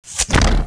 fireCrossbow.wav